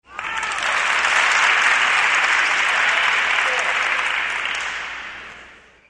Les sons de badminton
Applaudissements
applaudissements.mp3